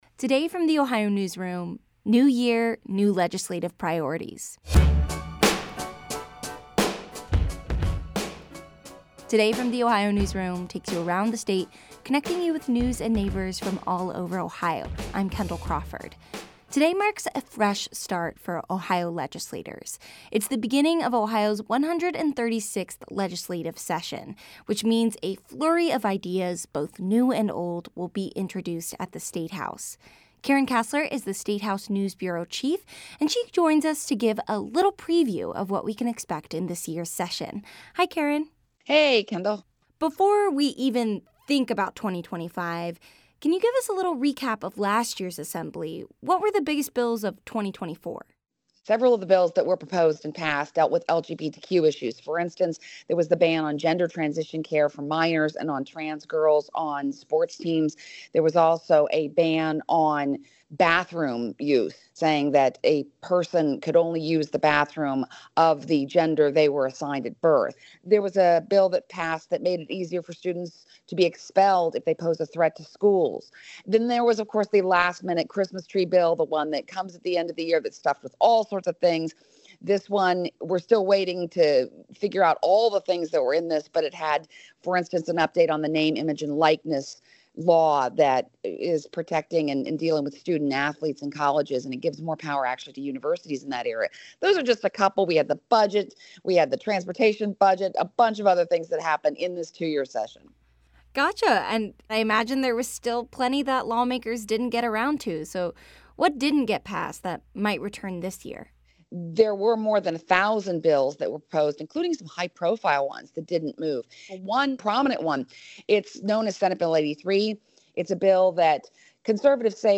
This interview has been edited for clarity and brevity.